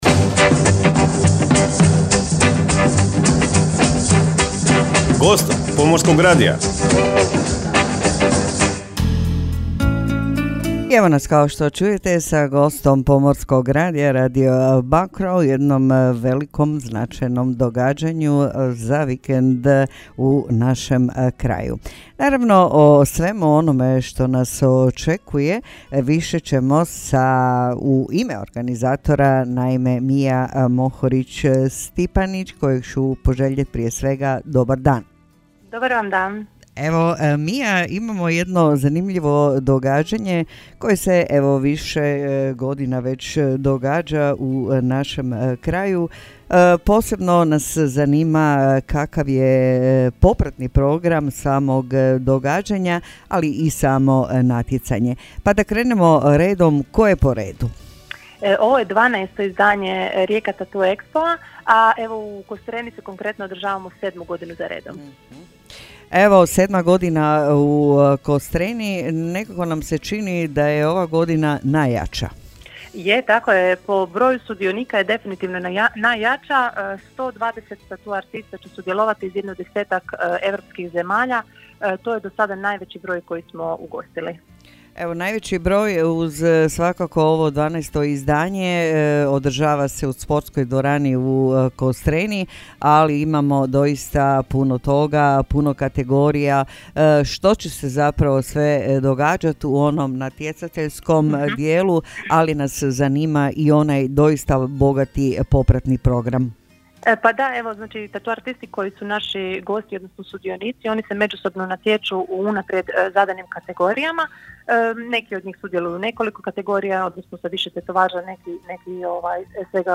– INTERVJU